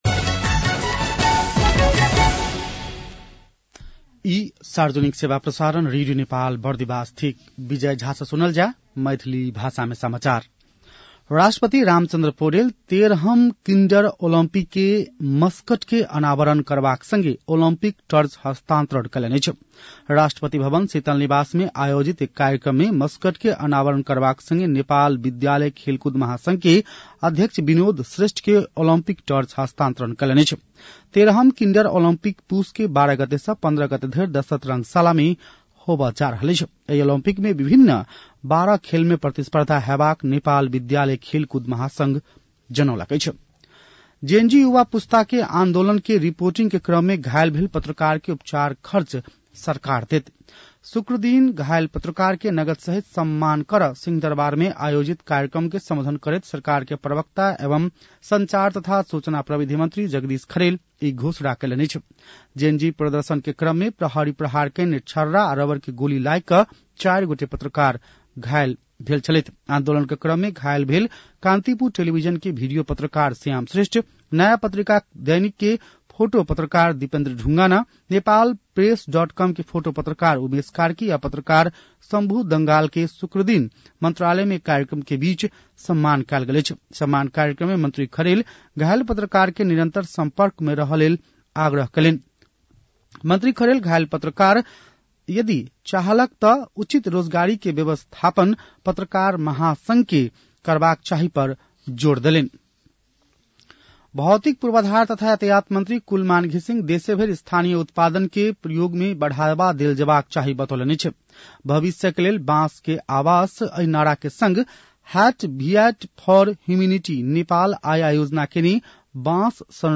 मैथिली भाषामा समाचार : ४ पुष , २०८२
Maithali-news-9-04.mp3